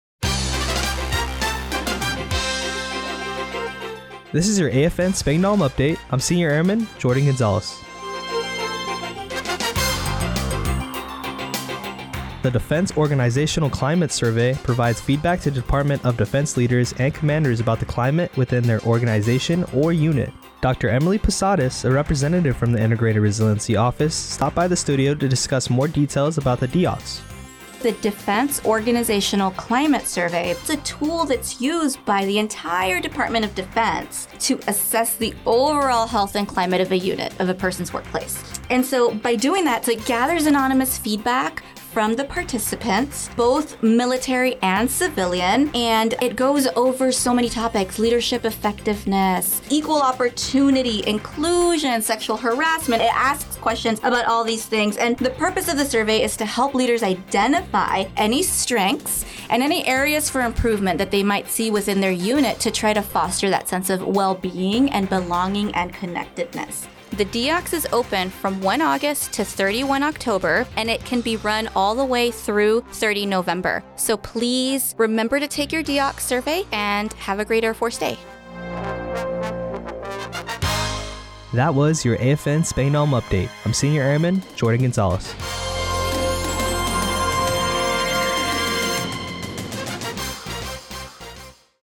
The following was the radio news report for AFN Spangdahlem for 16 Sept. 2024.